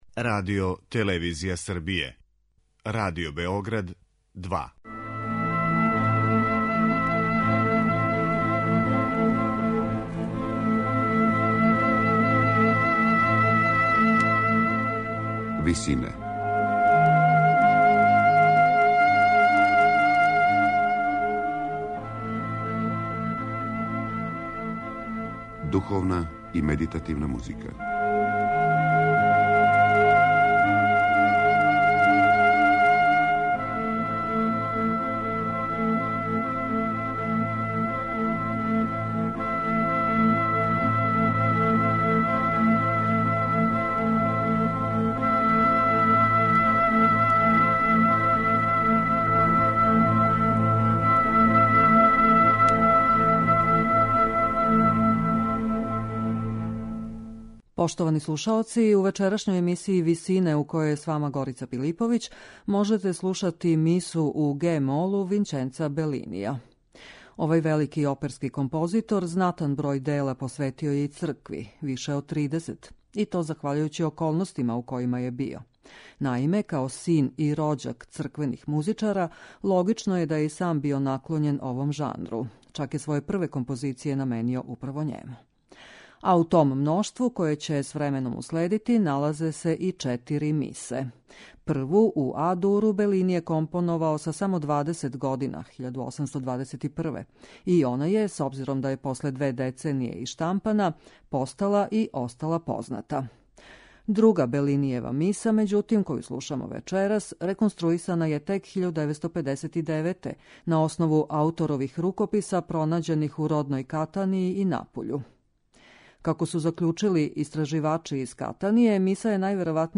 Црквена музика Вићенца Белинија